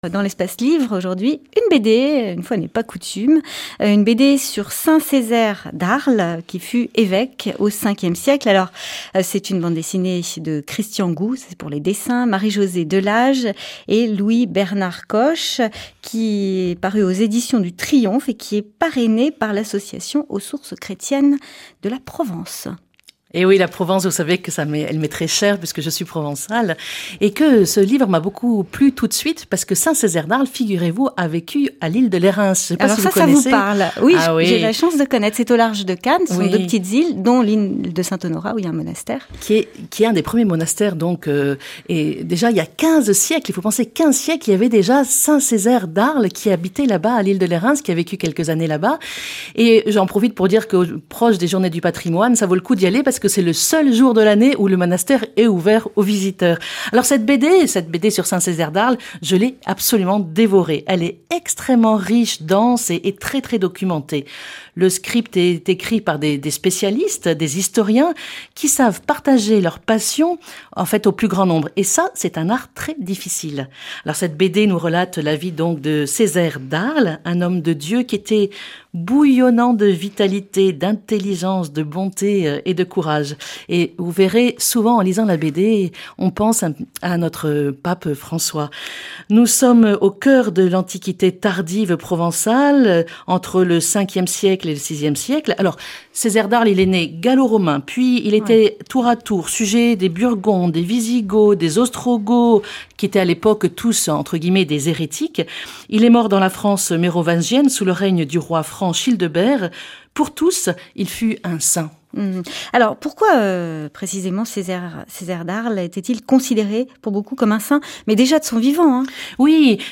BD Césaire d'Arles - Emission de radio